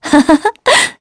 Veronica-Vox_Happy2.wav